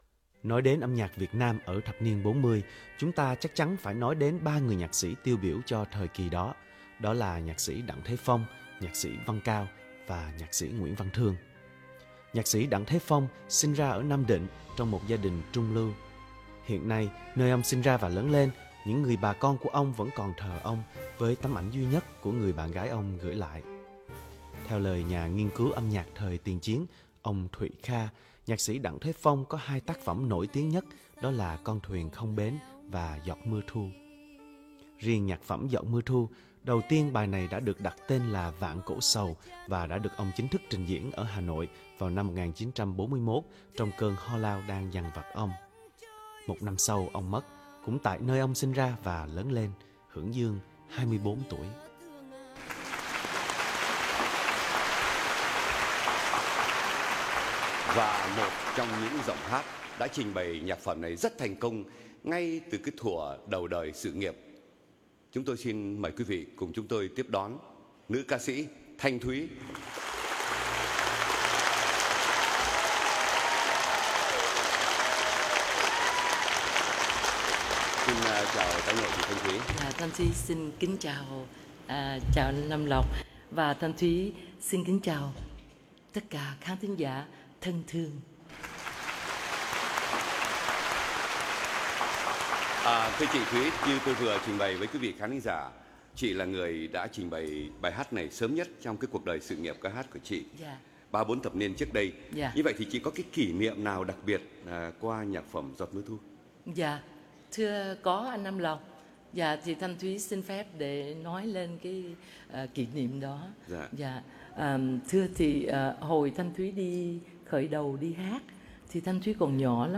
giot-mua-thu-phong-van.mp3